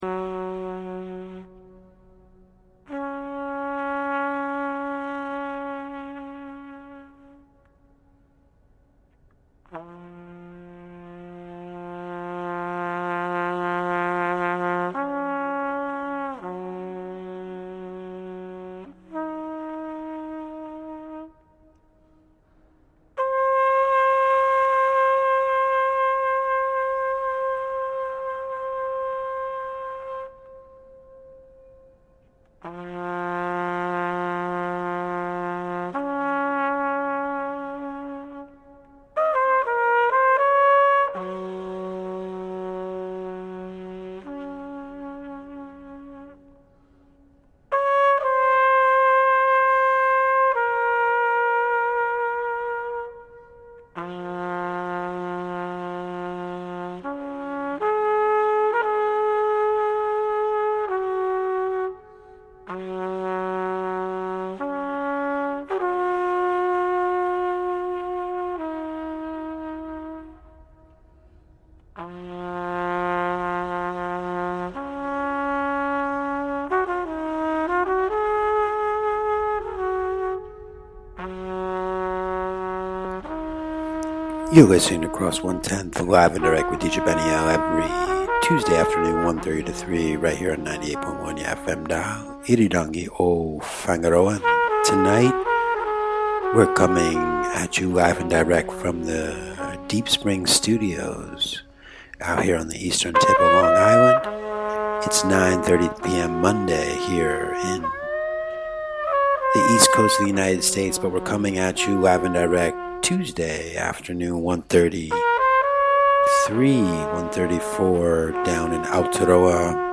lots of new soul, new jazz, new world music!